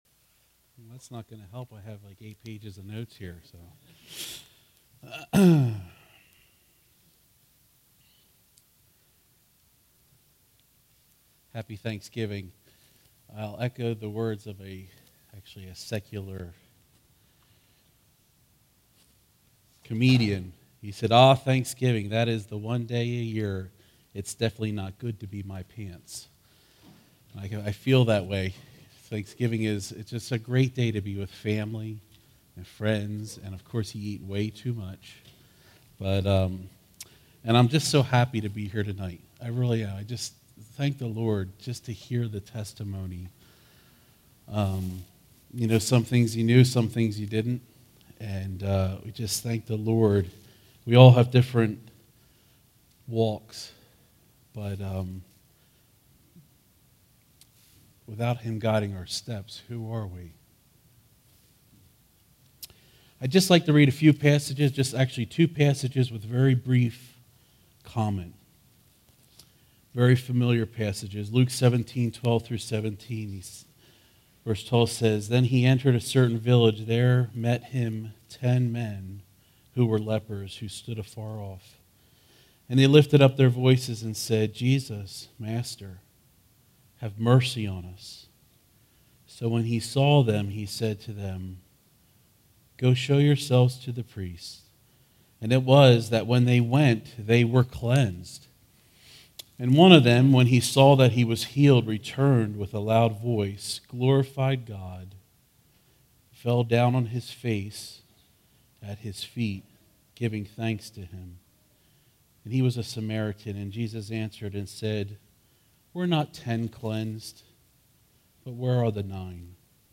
Thanksgiving Eve Service
All Sermons Thanksgiving Eve Service November 22